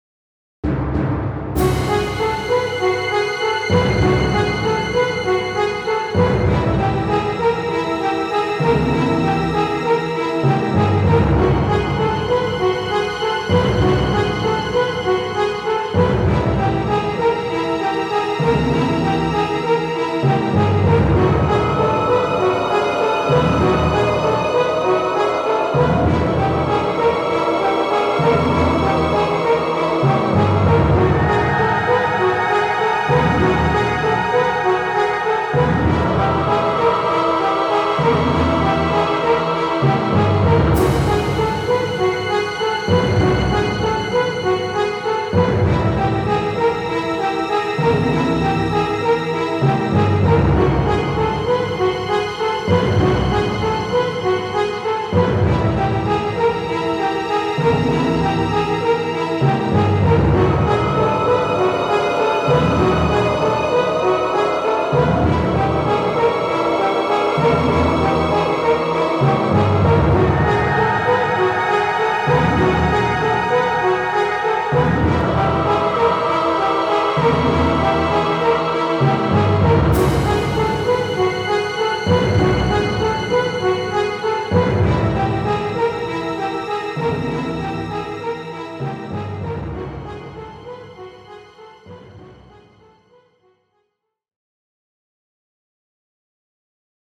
Symphonic Orchestra
Symphonic Choirs
Hollywood Brass
Guitar & Bass